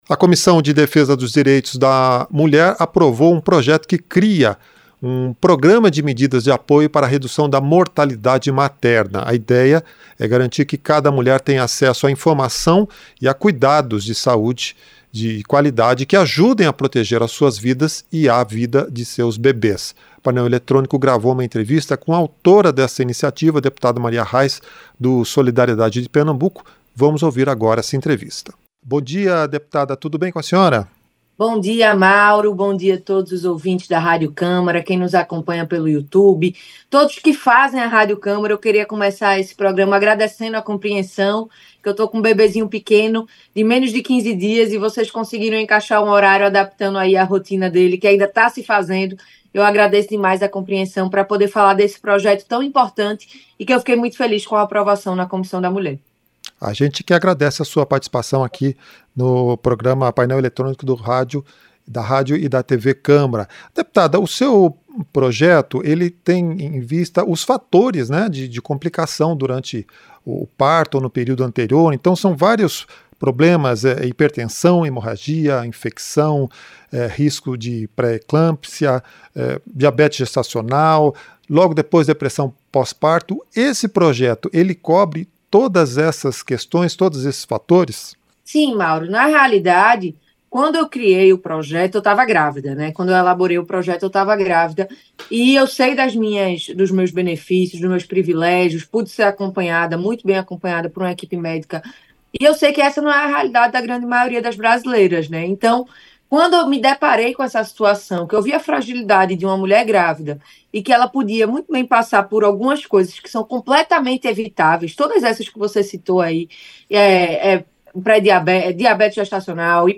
Entrevista - Dep. Marília Arraes (Solidariedade-PE)